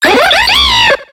Grito de Swoobat.ogg
Grito_de_Swoobat.ogg